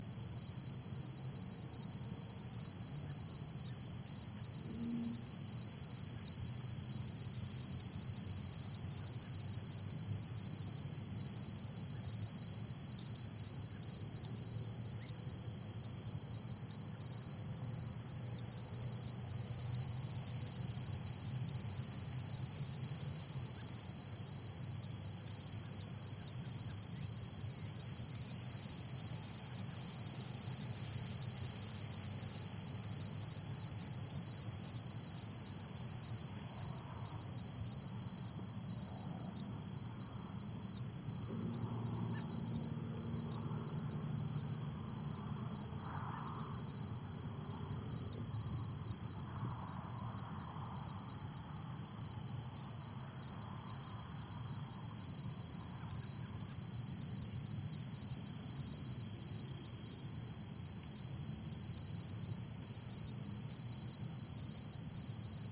Звуки рассвета
На этой странице собраны звуки рассвета — нежные трели птиц, шелест листвы и другие утренние мотивы.
Шепот утра в поле на рассвете Солнца